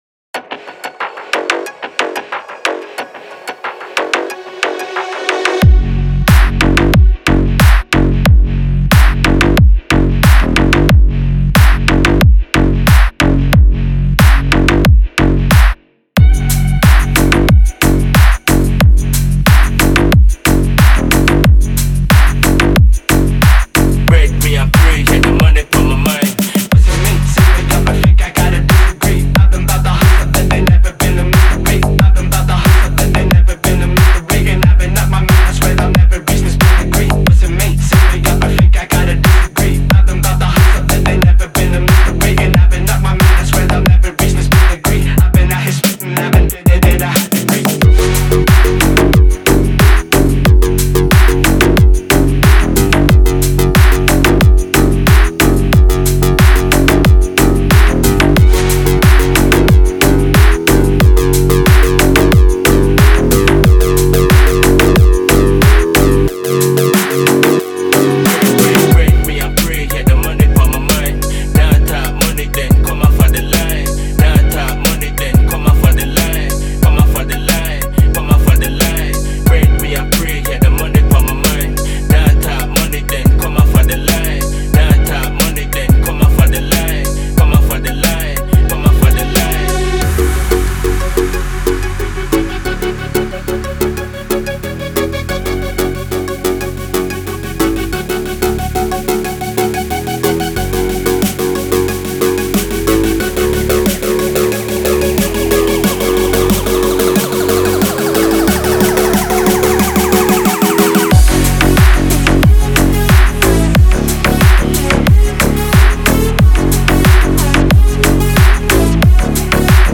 КЛУБНЯК в МАШИНУ